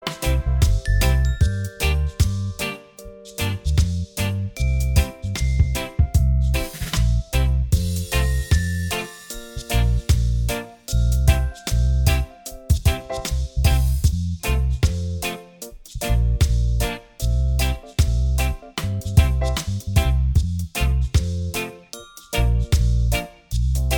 Minus Main Guitar Reggae 3:56 Buy £1.50